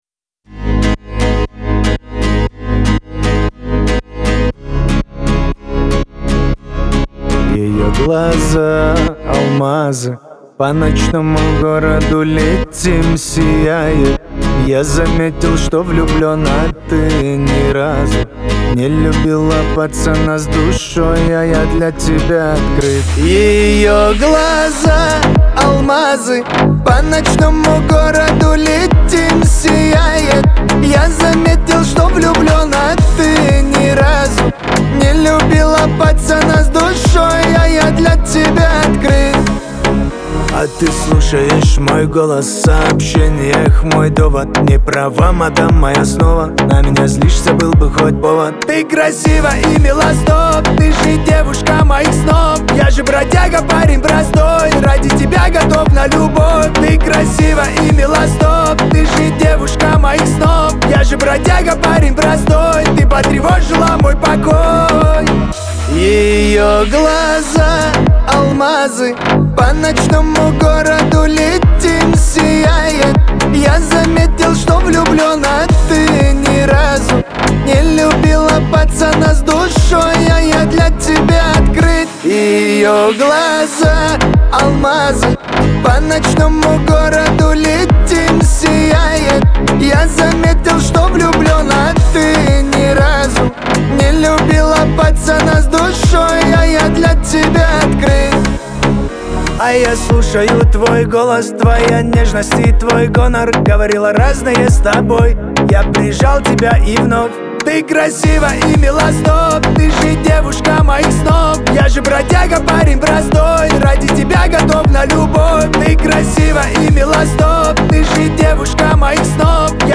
Жанр: Шансон, Поп, Русская Эстрада